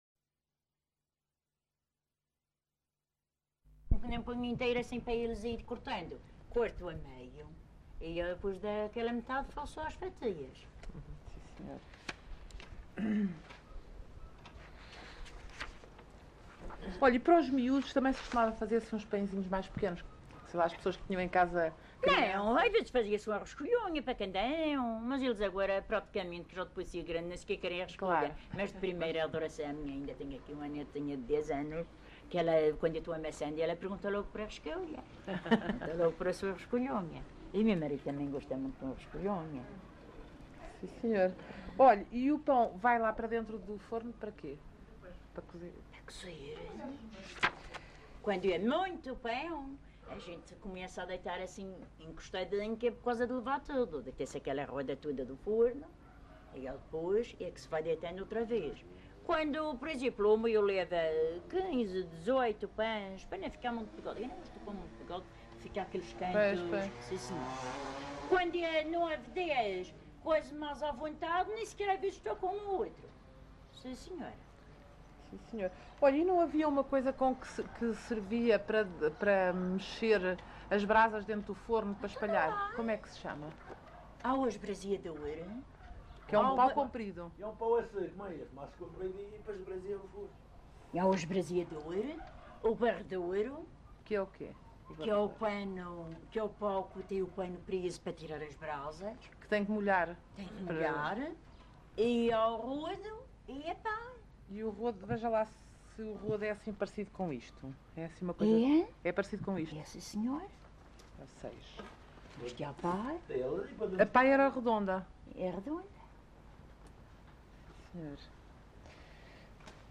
LocalidadeCamacha (Porto Santo, Funchal)